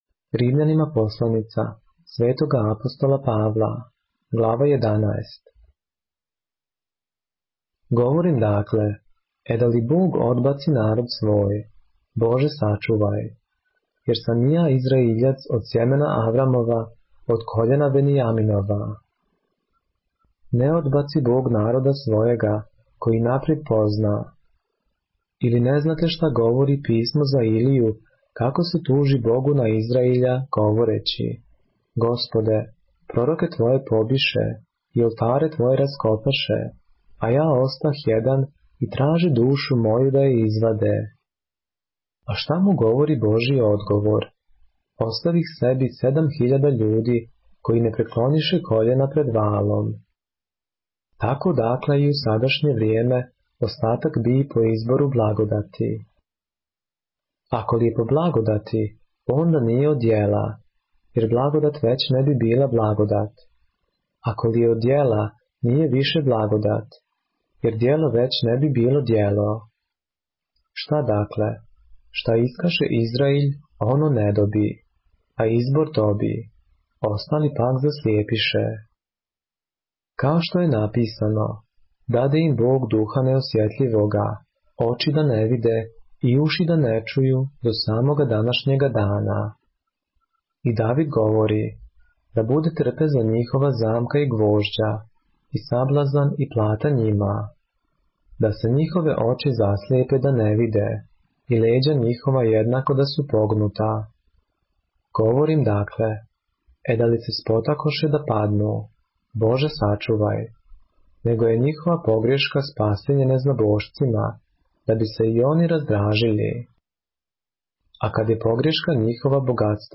поглавље српске Библије - са аудио нарације - Romans, chapter 11 of the Holy Bible in the Serbian language